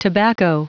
Prononciation du mot tobacco en anglais (fichier audio)
Prononciation du mot : tobacco